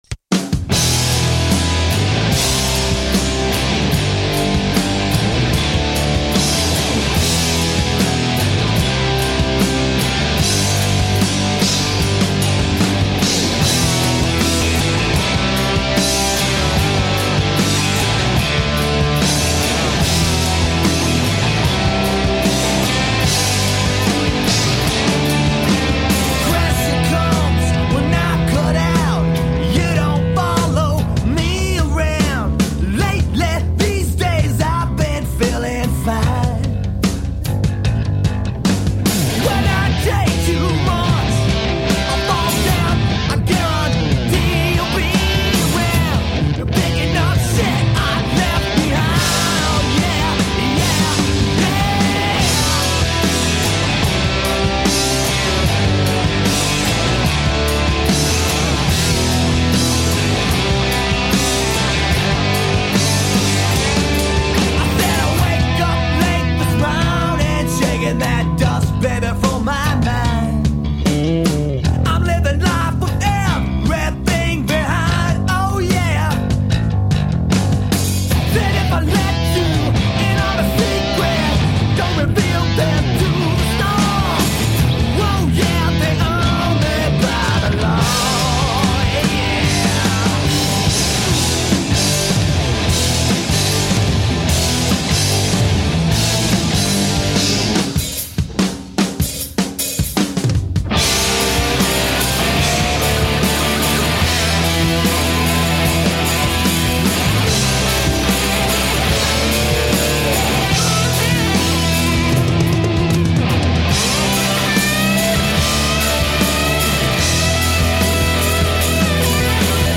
Obnoxious high-energy rock.
Biting guitars and gritty, pissed off rock vocals anyone?